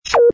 ui_close_infocard.wav